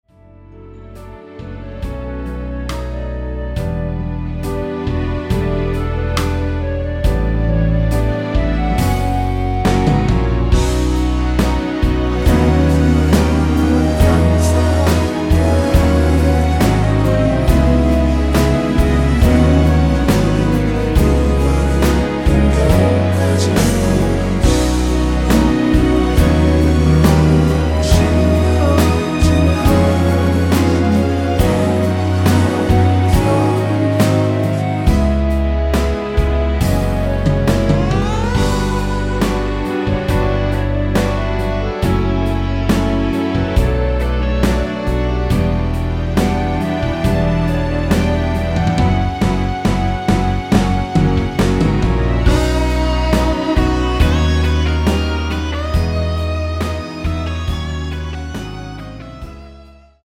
원키에서(-2)내린 멜로디와 코러스 포함된 MR입니다.(미리듣기 확인)
◈ 곡명 옆 (-1)은 반음 내림, (+1)은 반음 올림 입니다.
앞부분30초, 뒷부분30초씩 편집해서 올려 드리고 있습니다.